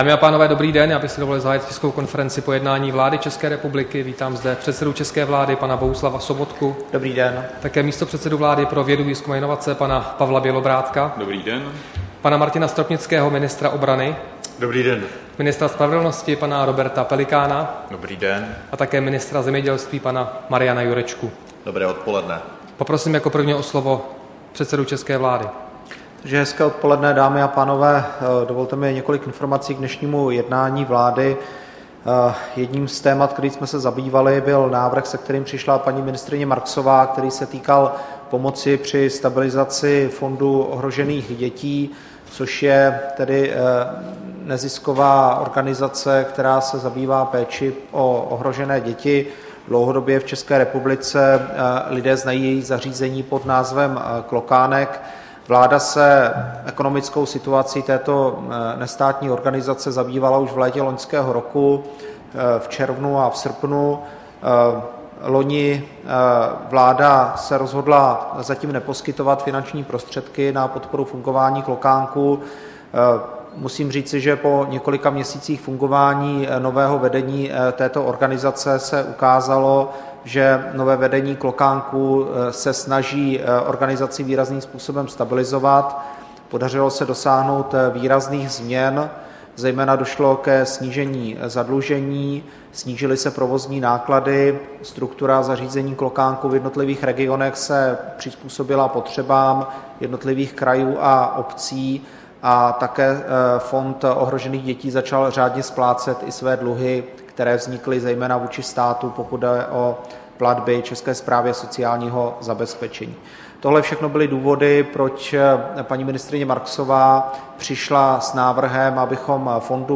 Tisková konference po jednání vlády, 30. března 2016